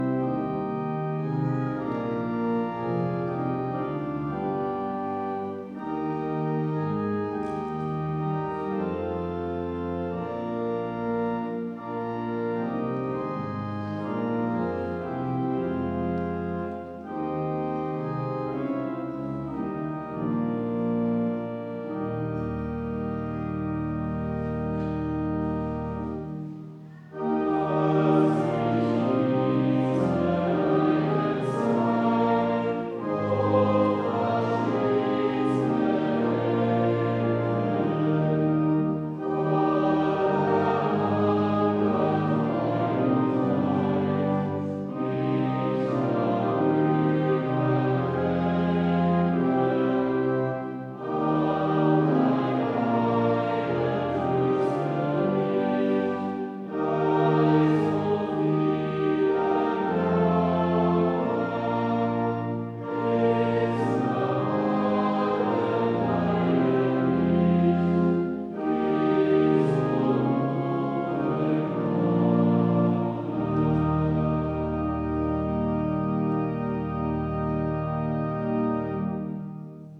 Audiomitschnitt unseres Gottesdienstes vom Palmsonntag 2026.